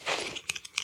equip_diamond3.ogg